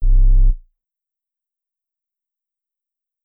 808 (Pigions).wav